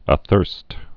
(ə-thûrst)